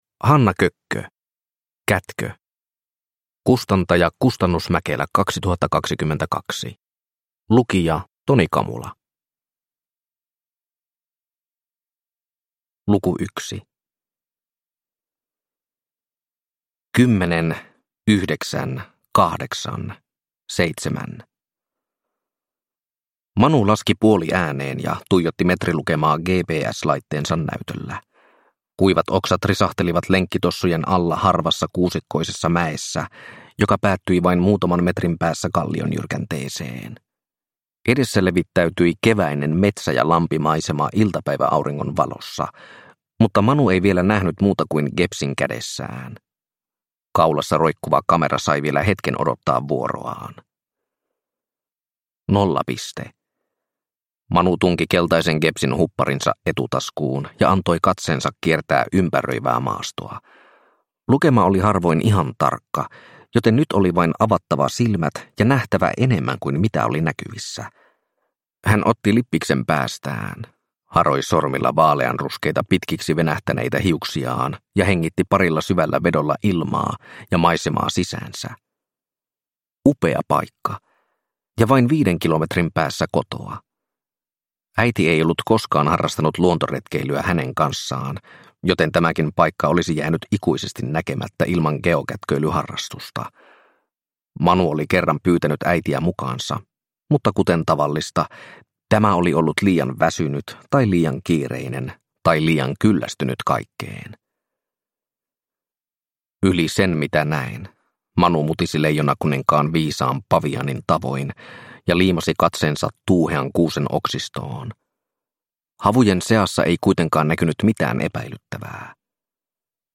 Kätkö – Ljudbok – Laddas ner